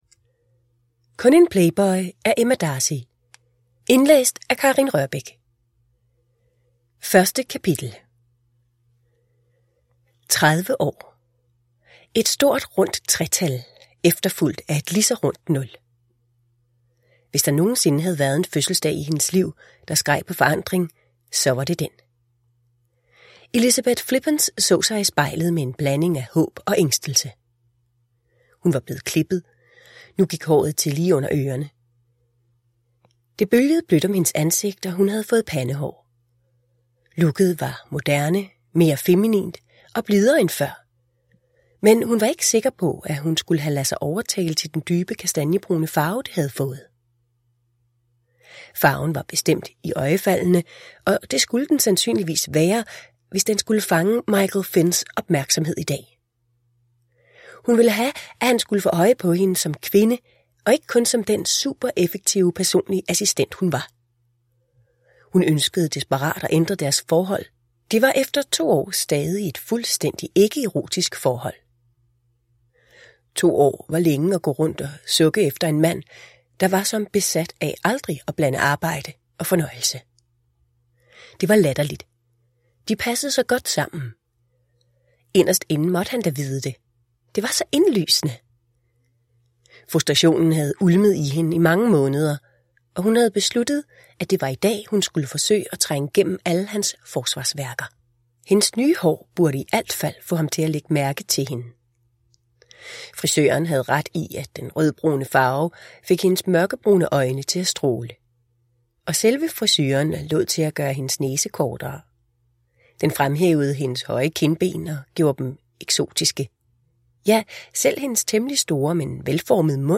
Kun en playboy? – Ljudbok – Laddas ner
Produkttyp: Digitala böcker